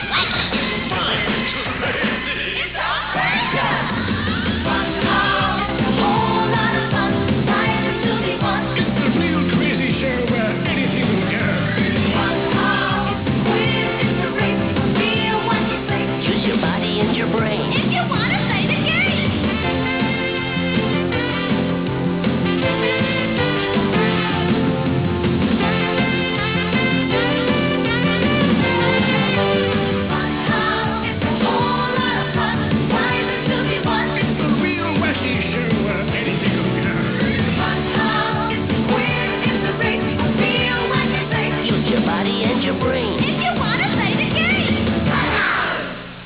Theme Tune